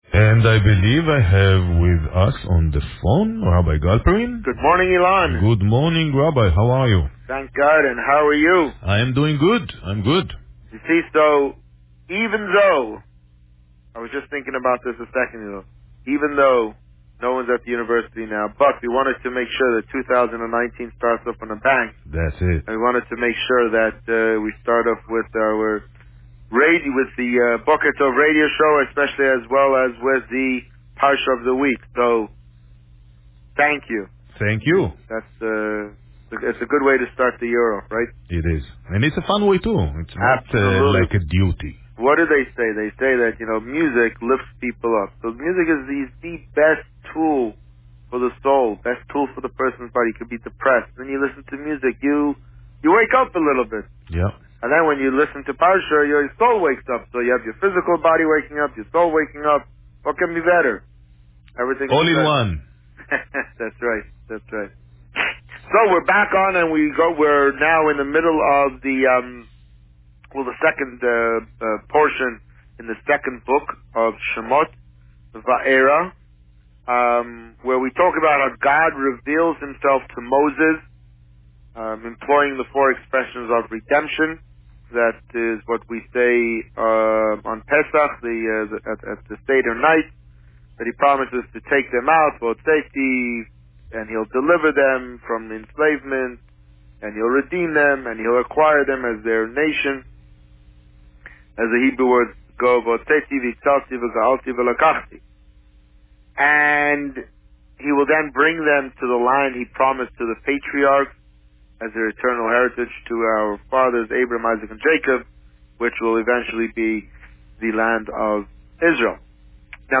This week, the Rabbi spoke about Parsha Va'eira. Listen to the interview here.